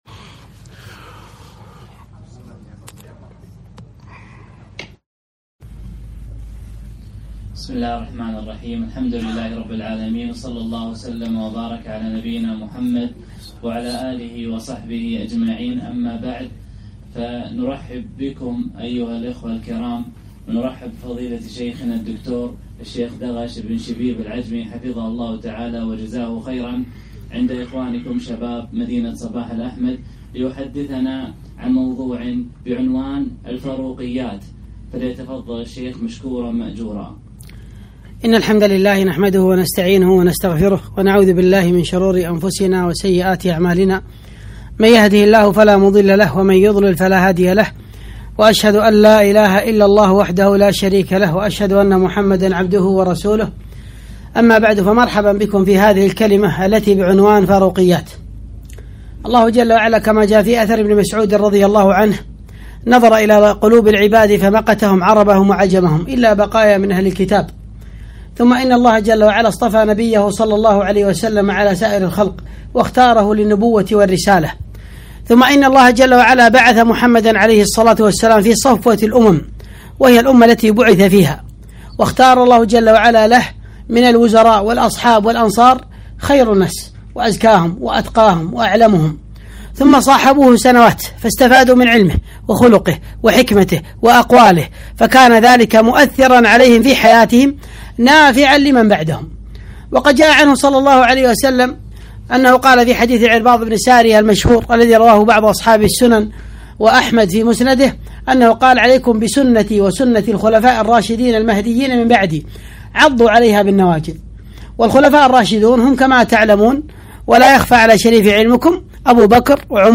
محاضرة - فاروقيات